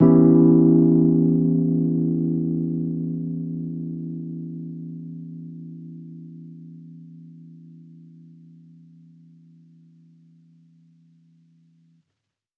Index of /musicradar/jazz-keys-samples/Chord Hits/Electric Piano 1
JK_ElPiano1_Chord-Em6.wav